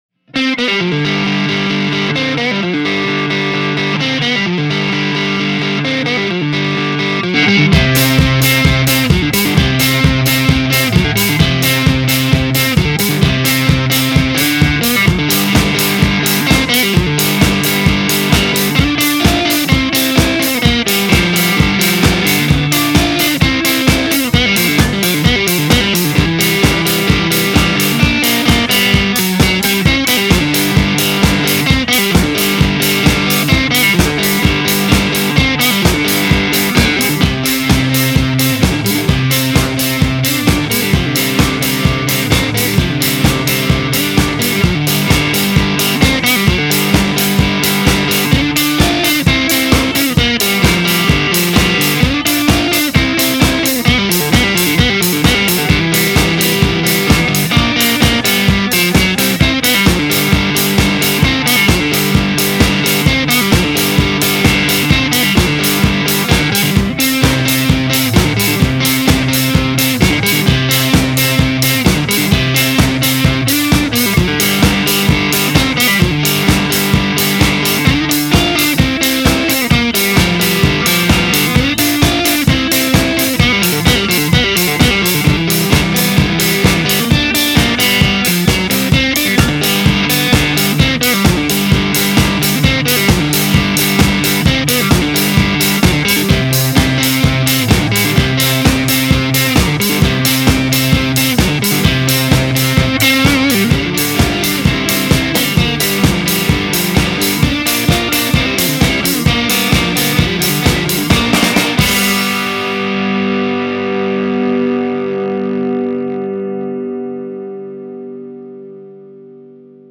instrumental tune
The guitars have a stereo/mono thing going on. When they're in stereo, it's my 50th Anniversary Strat on the left side, and my Ash Tele on the right. When the guitar is mono it's the strat alone. Both parts were recorded through the TC 15 - it was a while ago, so I don't remember any of the settings, but I believe it was the Orange 2x12 cab with V30's. I LOVE the tones on this.